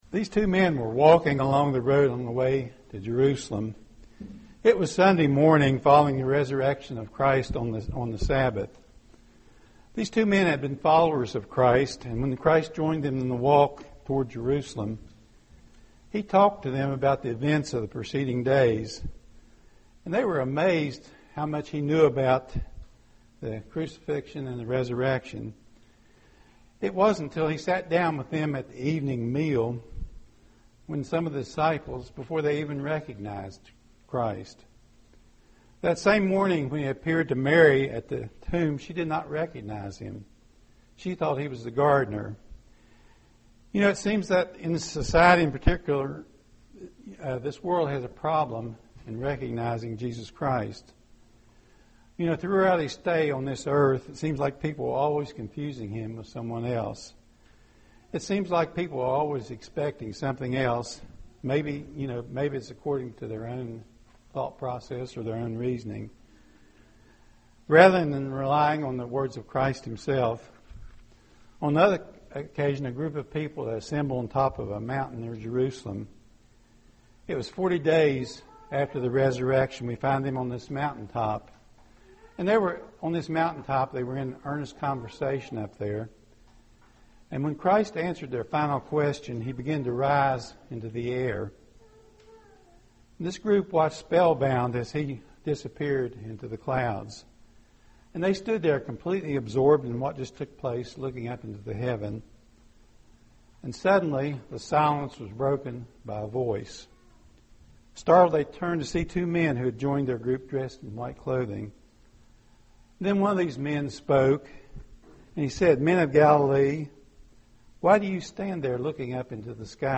Given in Roanoke, VA
UCG Sermon Studying the bible?